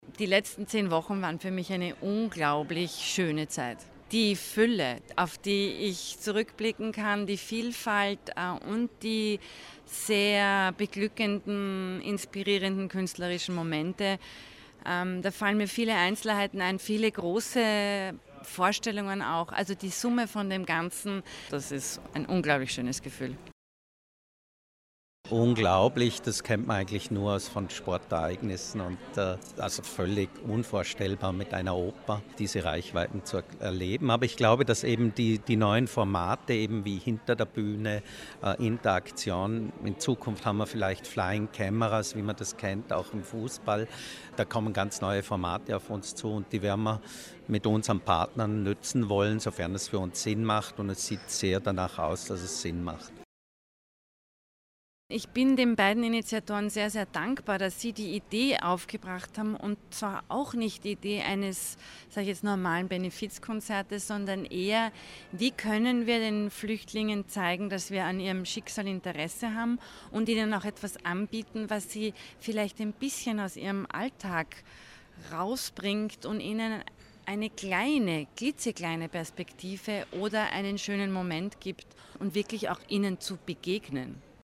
bregenz_endspurt-pk-feature.mp3